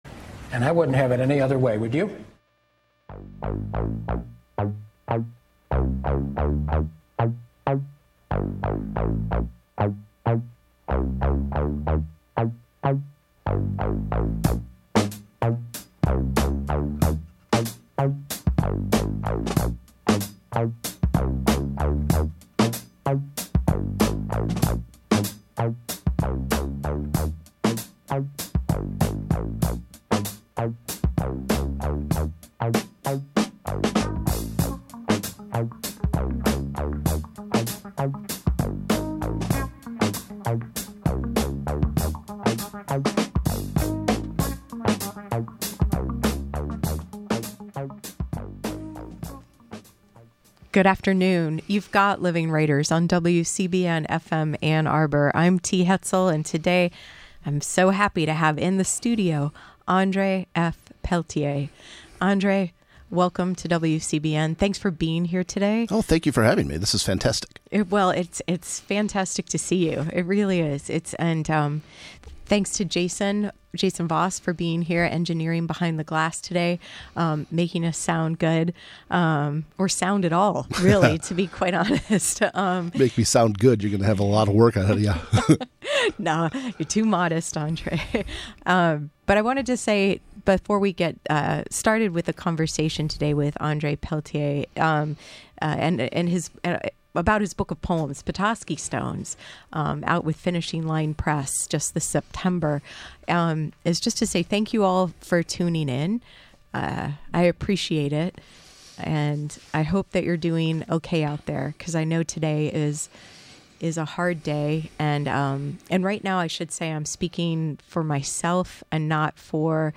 … continue reading 999 episodes # Books # Public Affairs # WCBN-FM Ann Arbor # WCBN-FM Ann Arbor